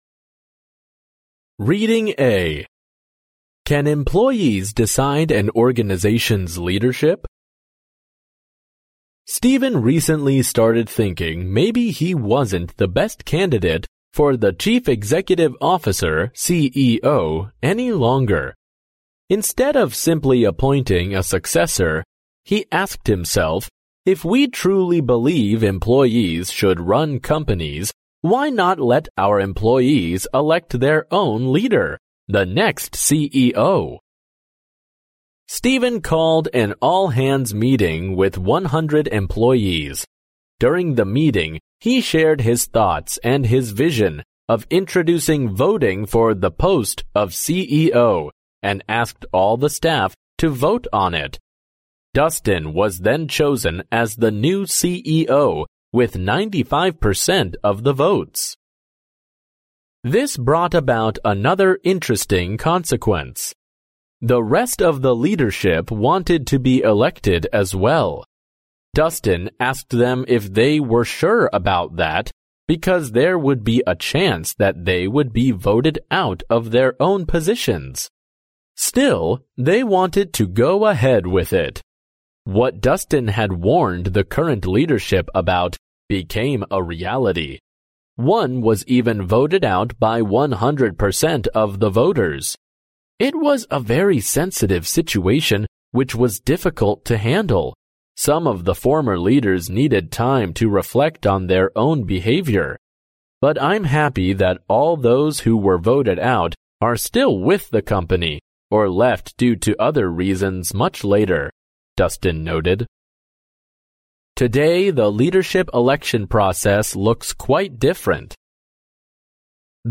第一册英语单词朗读录音